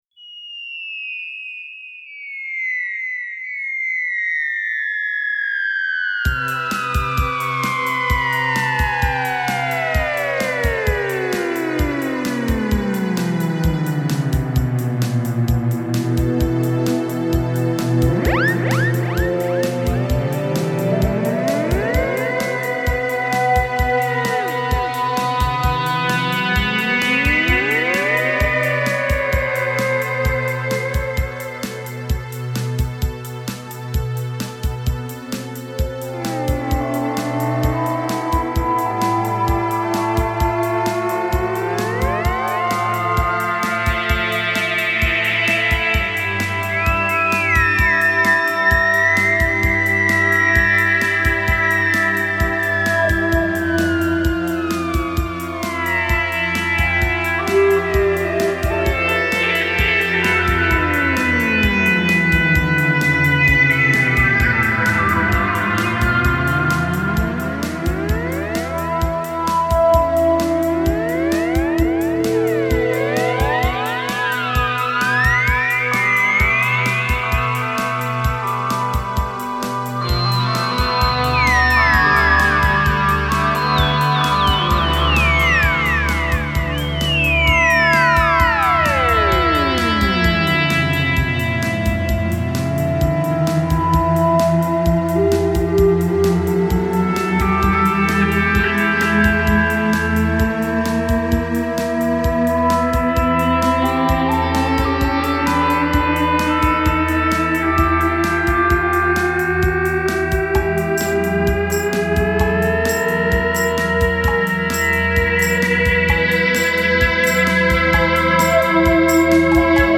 theremin, keyboards